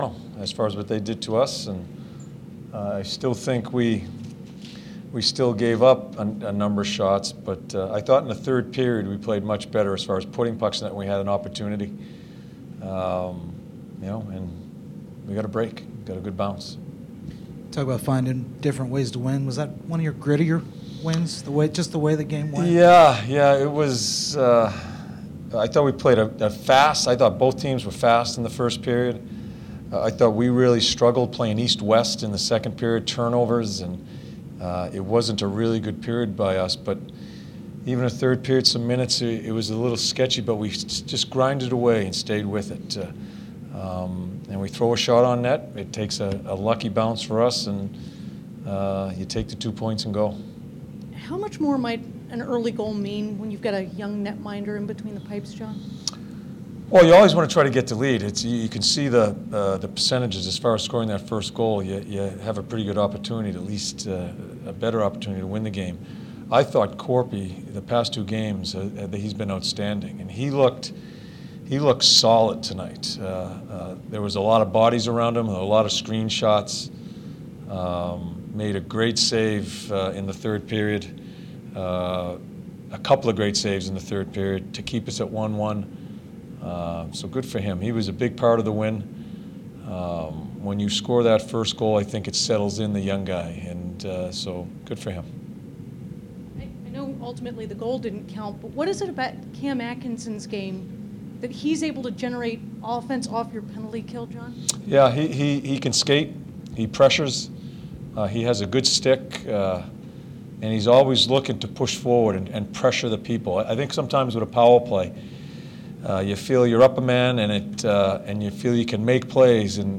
Head Coach John Tortorella addresses the media after 2-1 win against the Colorado Avalanche